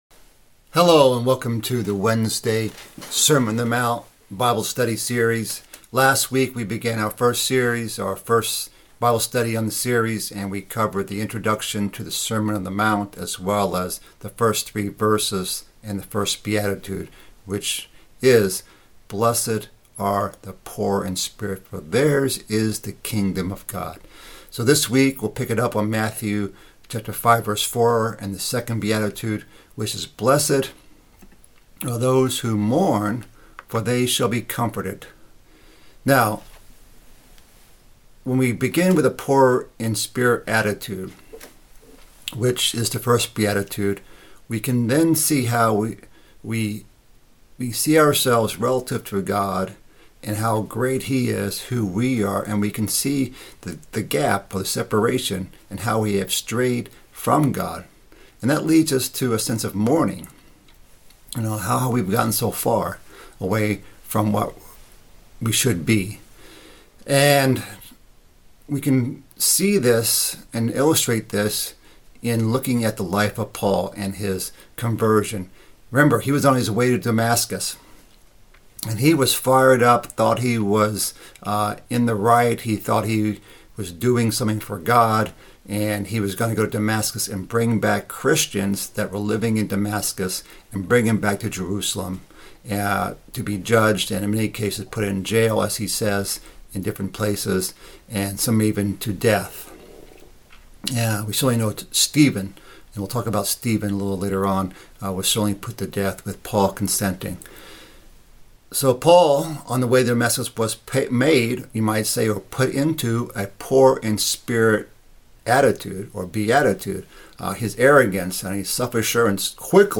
Bible Study - Sermon on the Mt. Part 2 - Matthew 5:4-7